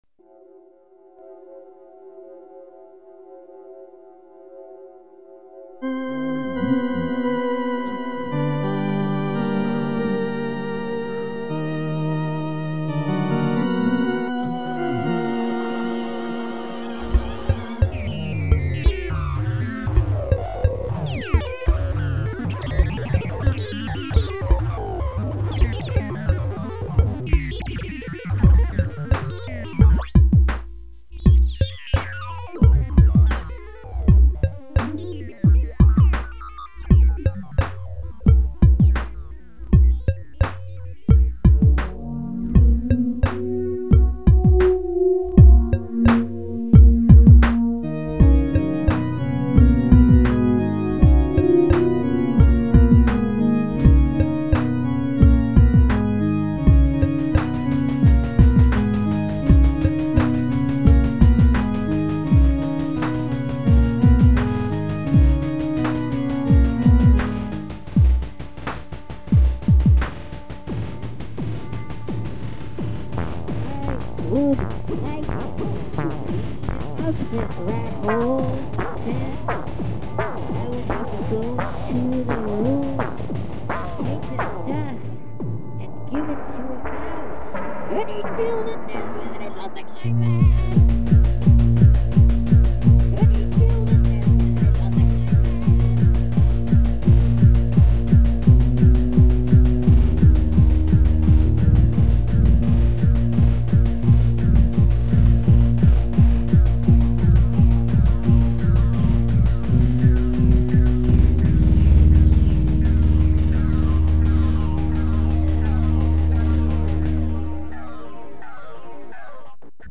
The various parts are layered on top of eachother with a cakewalk program.  The first sound you hear in the recording is a frequency modulation ring modulation combination.
The buzzing and ugliness is just more modulation but it's sequenced and also made using a sample and hold and adjusted as it's played.
The bass on Phx is a sine wave and a high pitched square wave on top.
The pads, which don't really hit Aphex's either, are pulse width modulated and filtered and they eco.
Most squeaky sounds are ring modulated and one oscillator swept up and down.
Machine sounds like those heard here in some parts of Phx come from modulation with fast LFOs.